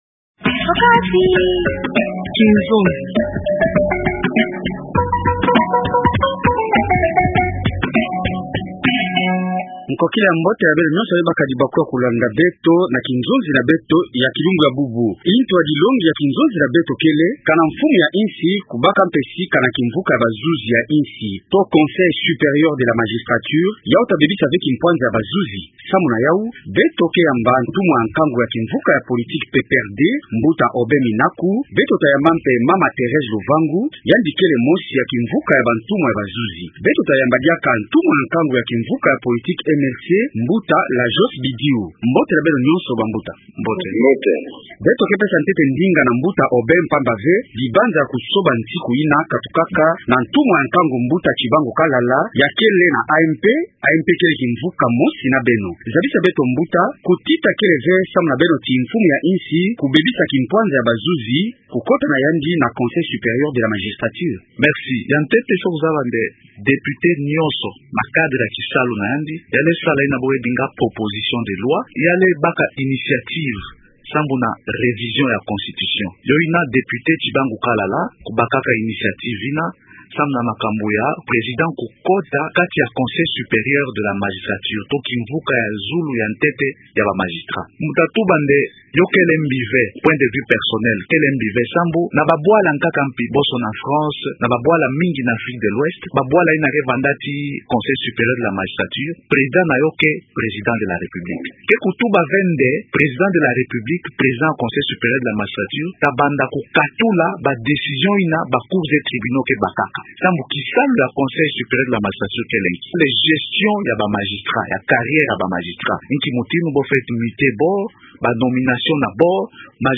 Bantumwa ya opposition, ya AMP, bwela diaka bayina ya société civile ta solula na nkokila ya bubu na intu ya dilongi yina.